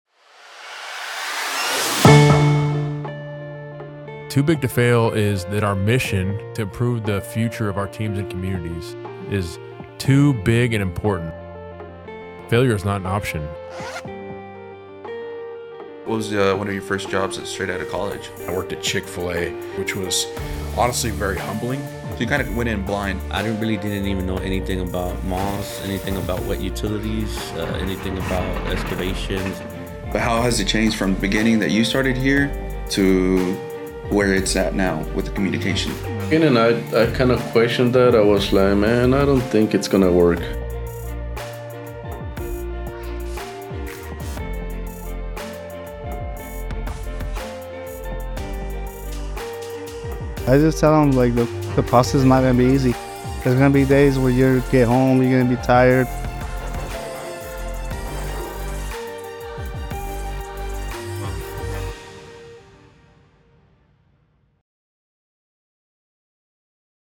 Too Big To Fail Trailer